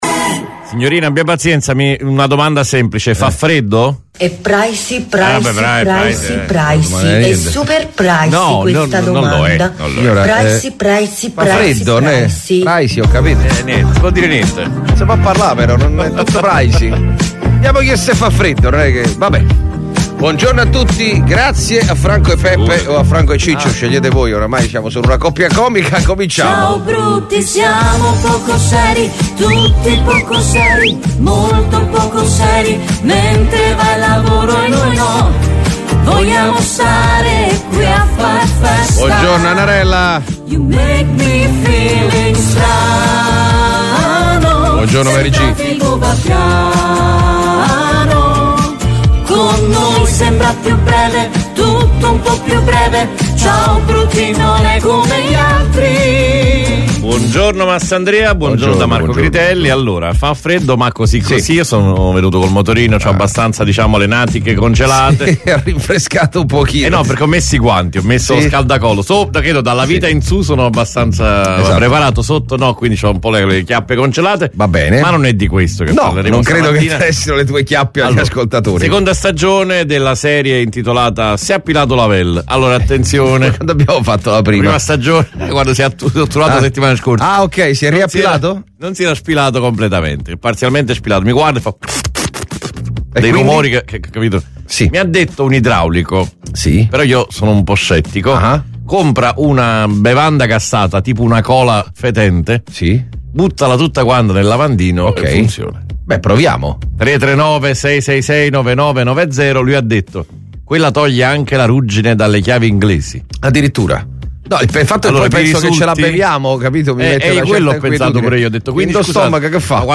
IL MORNING SHOW DI RADIO MARTE
TUTTE LE MATTINE DALLE 7 ALLE 9 DIVERTITI CON LE LORO PARODIE, I PERSONAGGI, GLI IMPROBABILI TALENTI DEL WEB E "L"AUDIOGADGET" DELLA SETTIMANA!